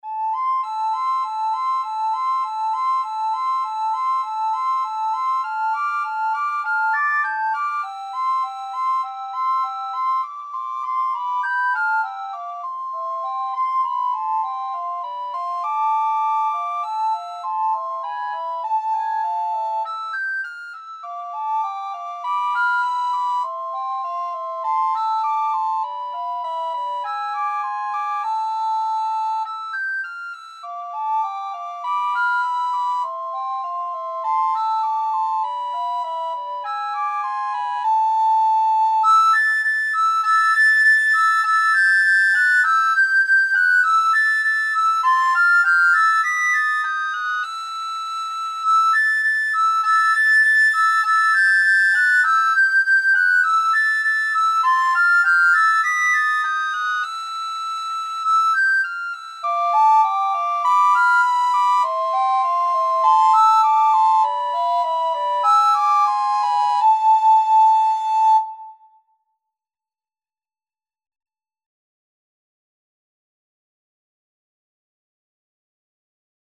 Free Sheet music for Recorder Duet
Recorder 1Recorder 2
A minor (Sounding Pitch) (View more A minor Music for Recorder Duet )
4/4 (View more 4/4 Music)
Moderato
Traditional (View more Traditional Recorder Duet Music)
world (View more world Recorder Duet Music)